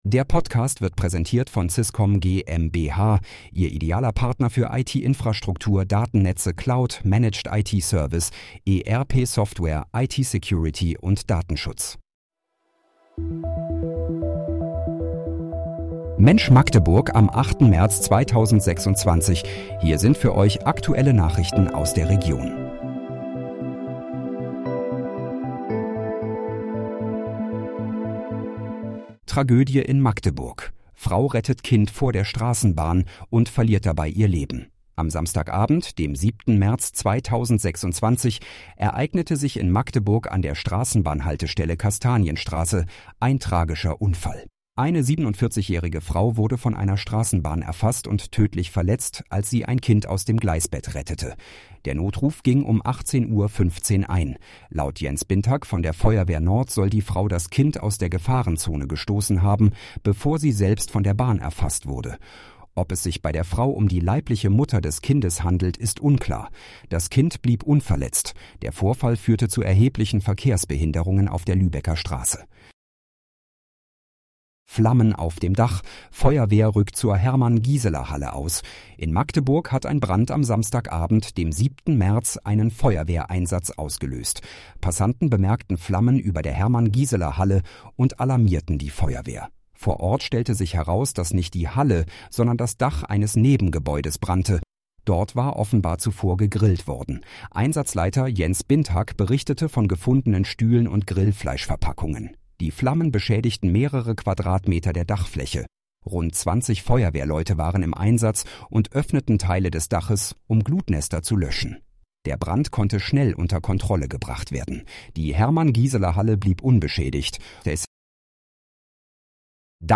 Mensch, Magdeburg: Aktuelle Nachrichten vom 08.03.2026, erstellt mit KI-Unterstützung